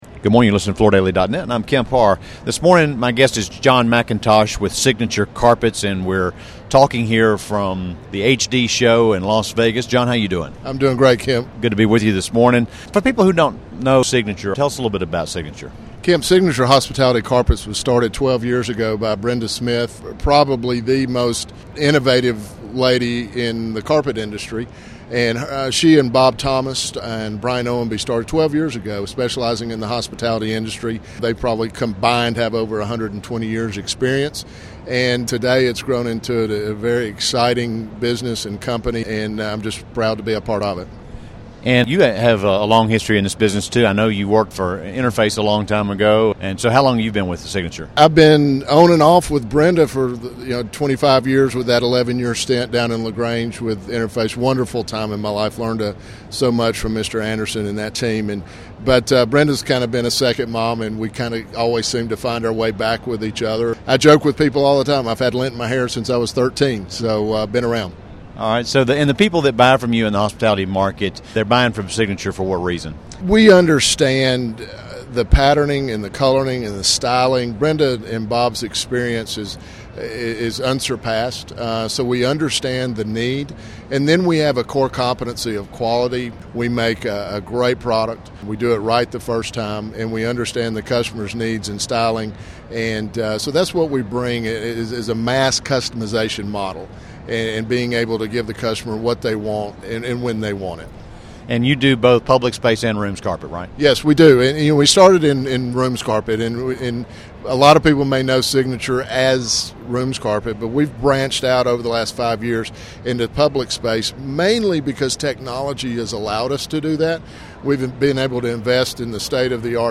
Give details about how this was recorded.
Listen to the interview recorded from the floor of the Hospitality Design show in Las Vegas to hear more details about what Signature is doing to set themselves apart in this market.